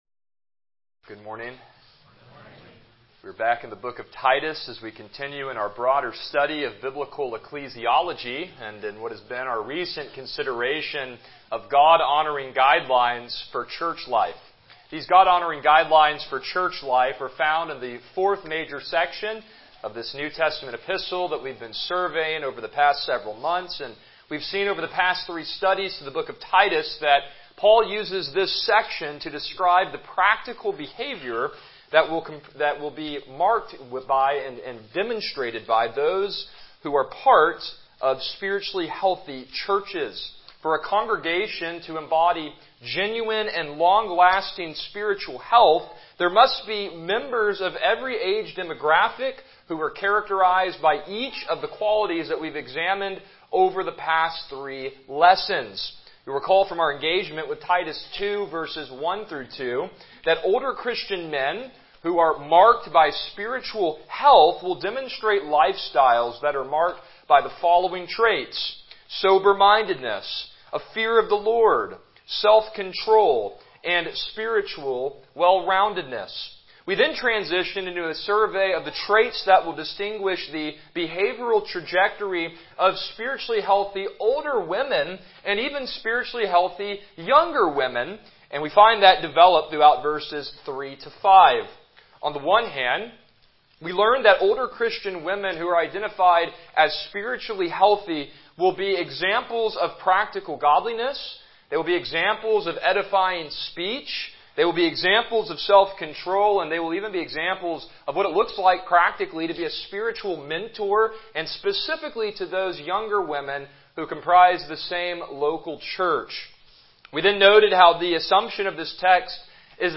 Passage: Titus 2:6-8 Service Type: Morning Worship